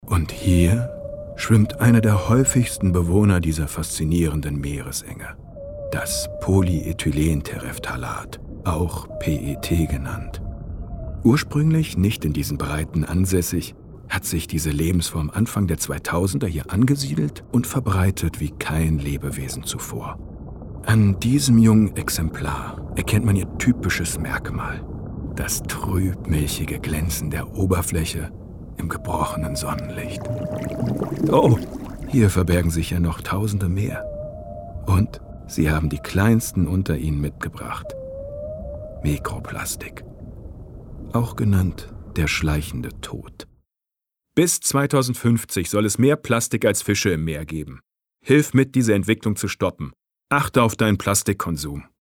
sehr variabel, markant
Mittel plus (35-65)
Commercial (Werbung)